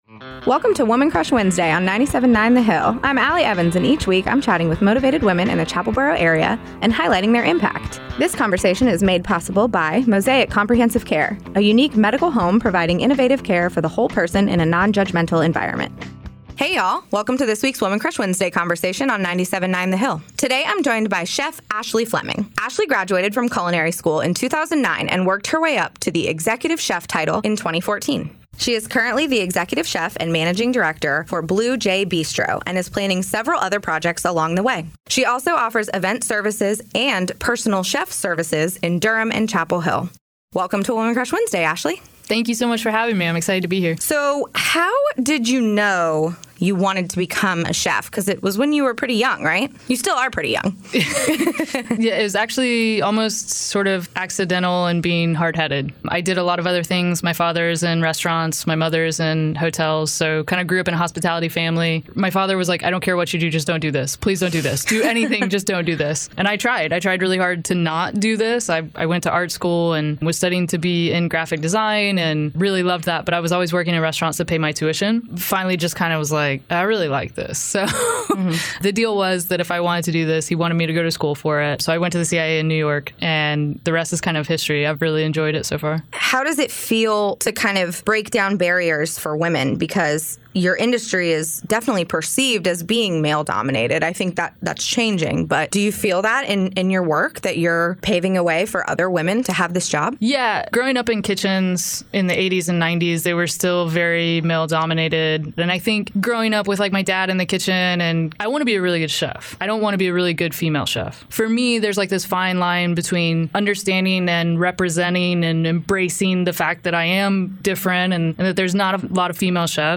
” a three-minute weekly recurring segment made possible by Mosaic Comprehensive Care that highlights motivated women and their impact both in our community and beyond.